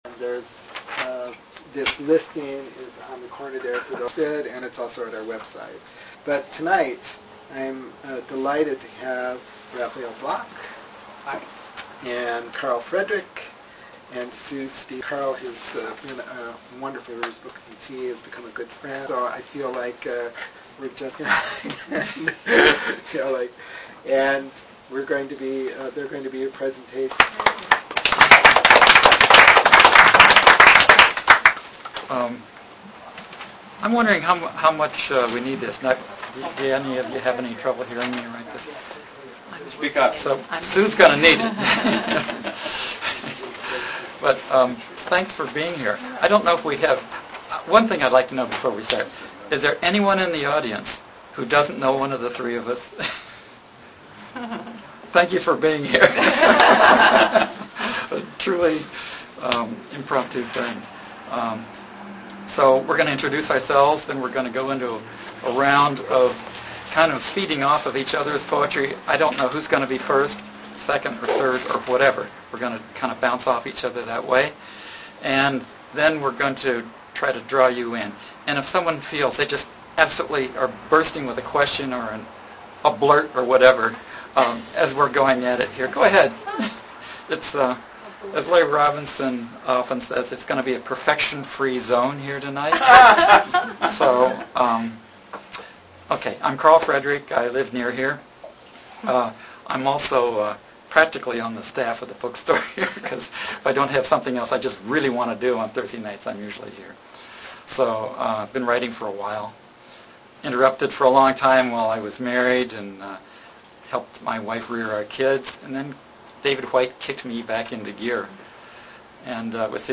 Archive of an event at Sonoma County's largest spiritual bookstore and premium loose leaf tea shop.
Three Sonoma County poets will engage in poetic dialogue with each other and all present.
They'll respond in kind to each others' poems and to prompts and/or questions from the audience.